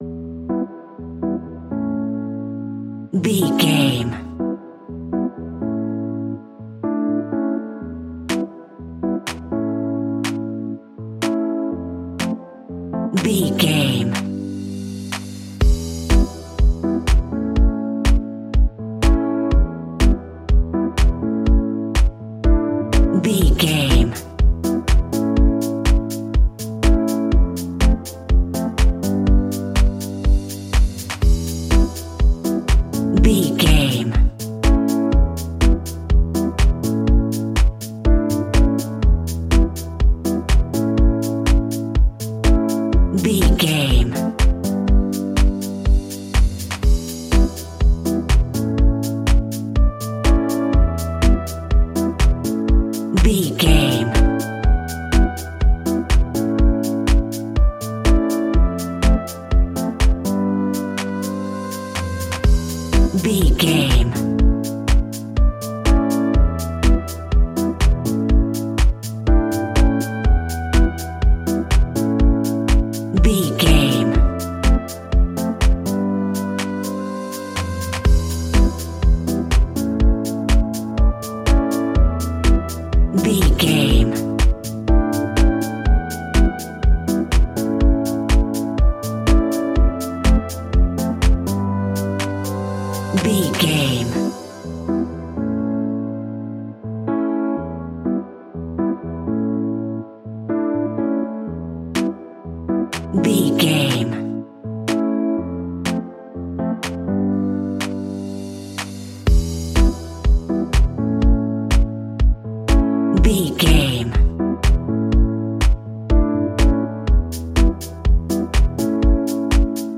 Aeolian/Minor
uplifting
energetic
bouncy
synthesiser
drum machine
electric piano
funky house
nu disco
groovy
upbeat
synth bass